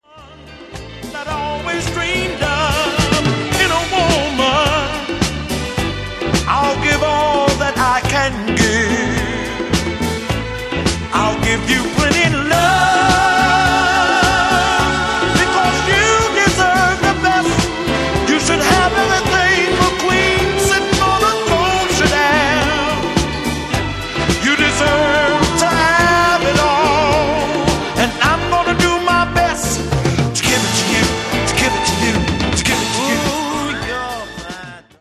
Genere:   Disco | Soul